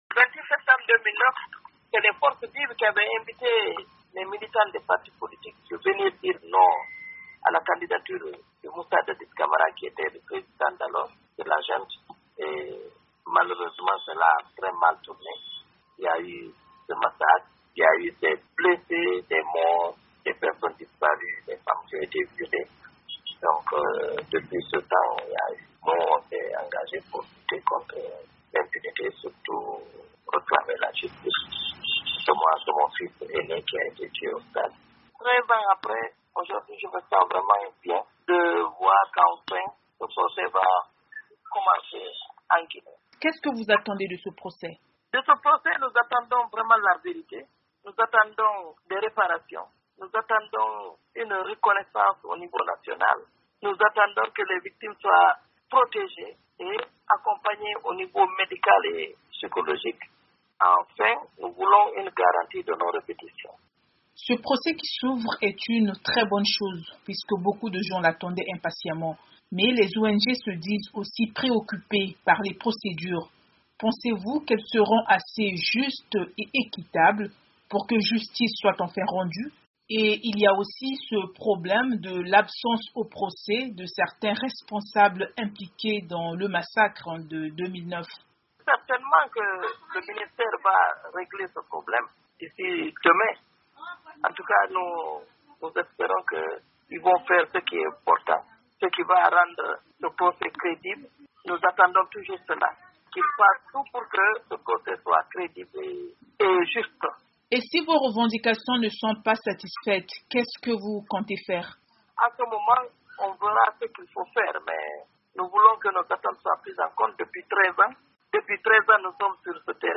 Massacre du 28-Septembre à Conakry: témoignage du père d'une victime